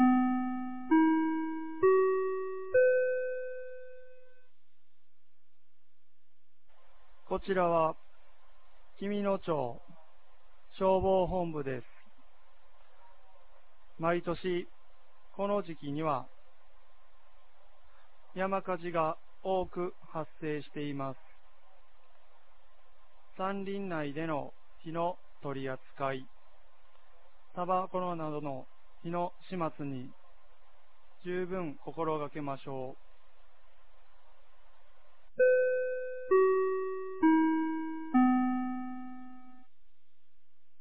2021年05月08日 16時00分に、紀美野町より全地区へ放送がありました。